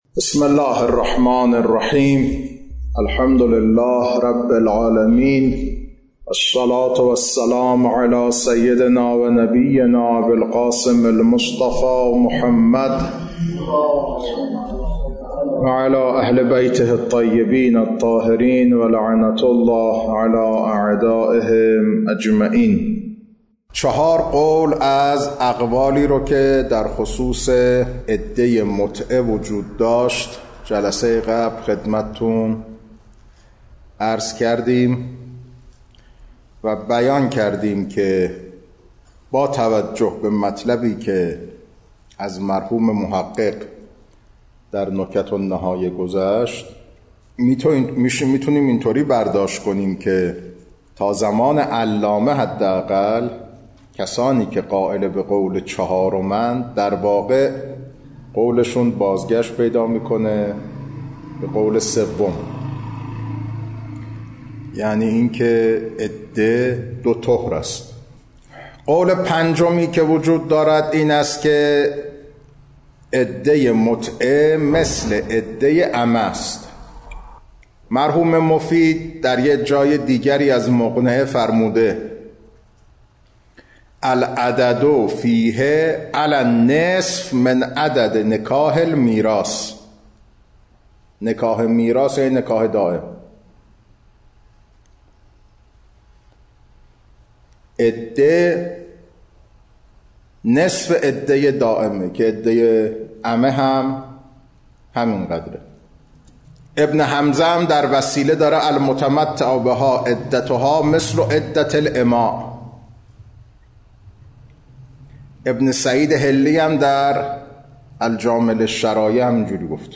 خارج فقه، بحث نکاح